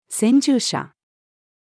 先住者-female.mp3